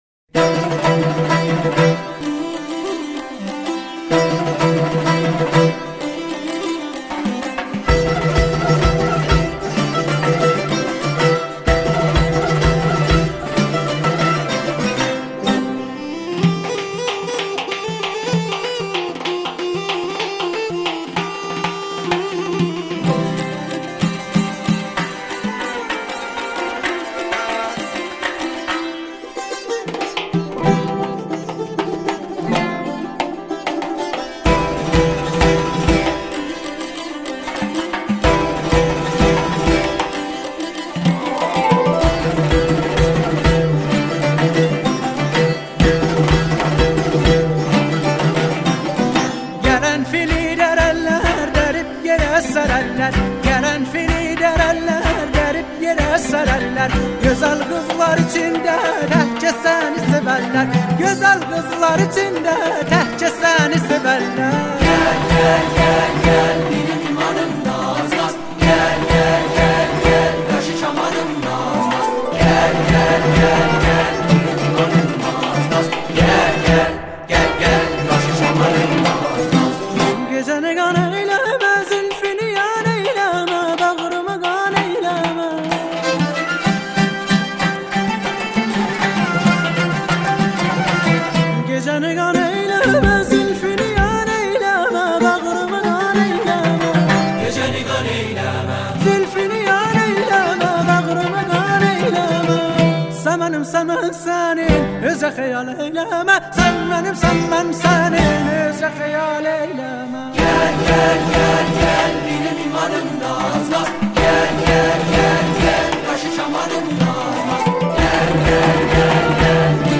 آهنگ آذري